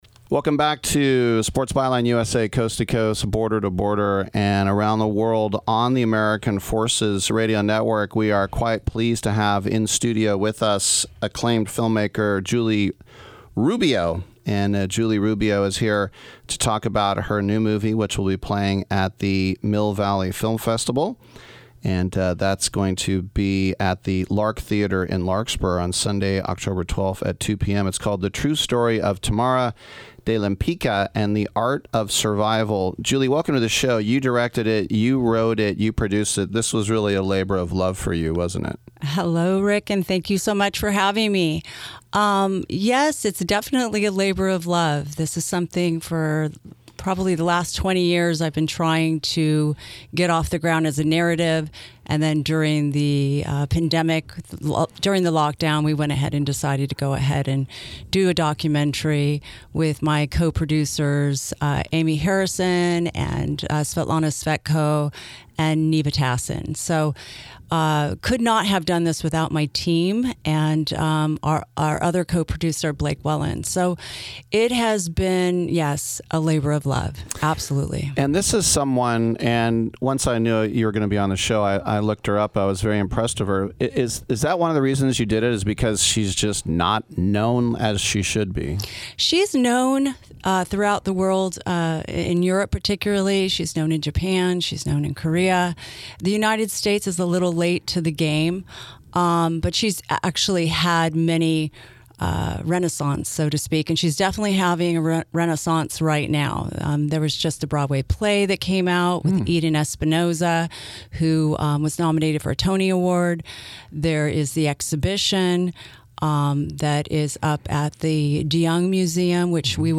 Listen to this review broadcasted